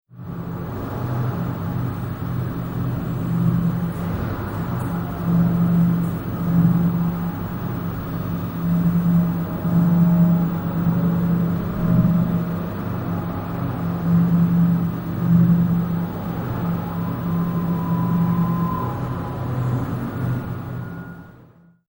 A system of amplification is used to audibly enhance and extrapolate what is already there - the peculiar resonant space created by the glass and reflective walls of the corridor.
The system assimilates and adapts to any sound made in the space, including incidental sound leaking into the system from elsewhere in the building.
A documentary fragment of a moment within the installation, recorded on 24 September 2003